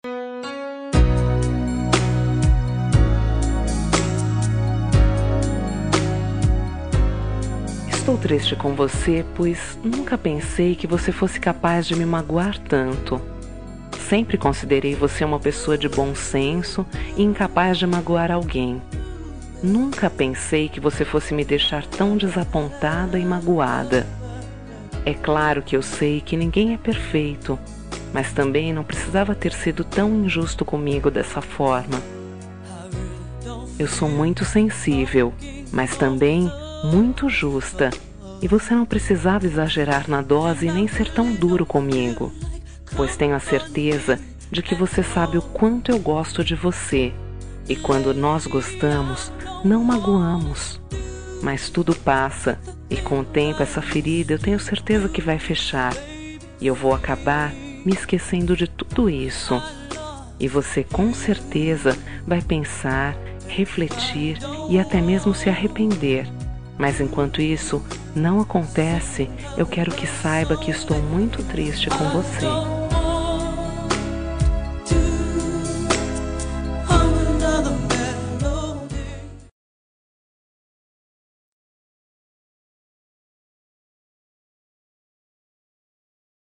Toque para Não Terminar – Voz Feminina – Cód: 468 – Triste com Você
468-triste-com-voce-fem.m4a